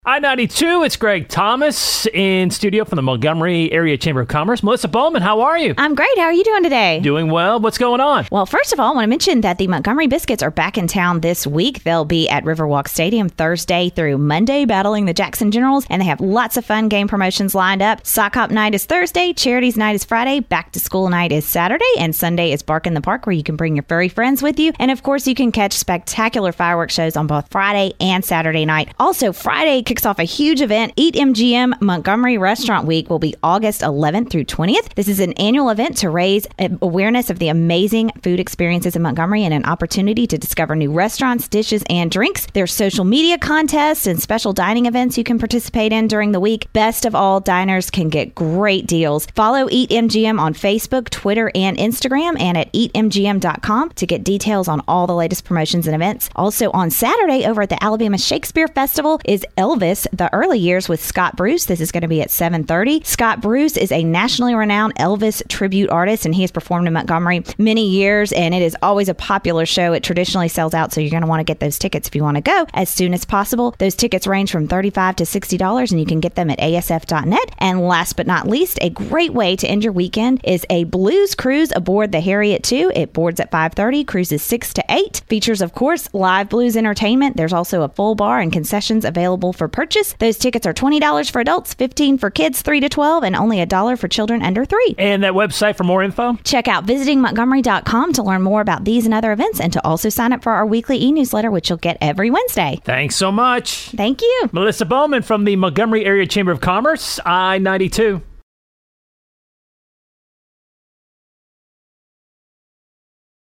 in studio to highlight events happening in the Montgomery area through the weekend!